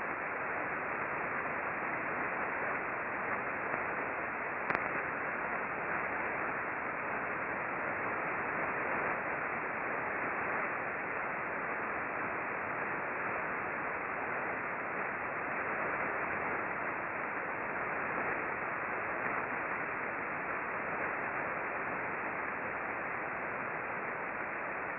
The ambient RF noise level in this direction is fairly high (over 300k K).
We observed mostly S-bursts that shifted from receiver to receiver during the bursting periods.